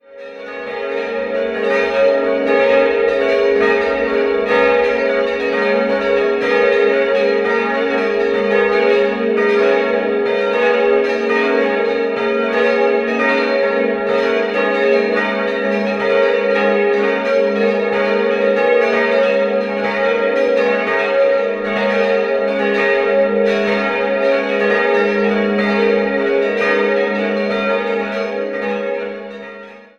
6-stimmiges Geläut: gis'-ais'-cis''-dis''-gis''-ais'' Die beiden kleinen Glocken wurden um das Jahr 1500 in Nürnberg gegossen und befanden sich, zusammen mit einer noch kleineren Glocke, im ehemaligen Schloss Schönberg.